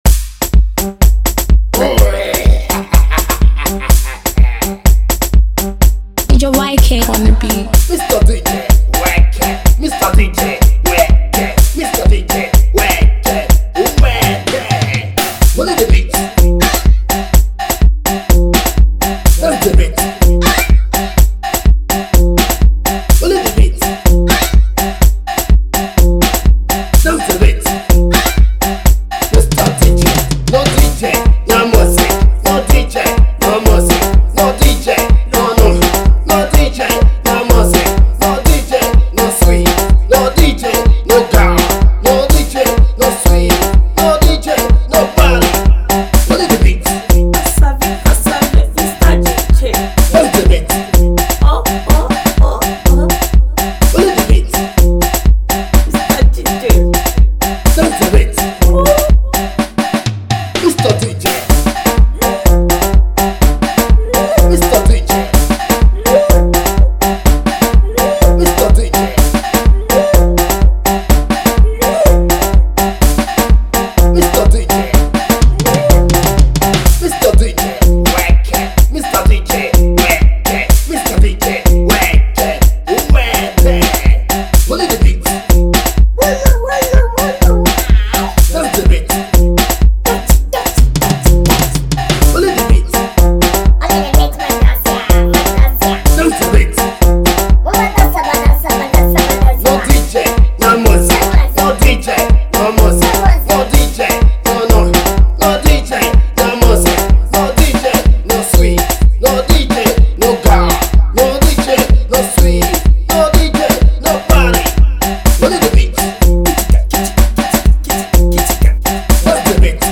freestyle of south Africa talk on his beat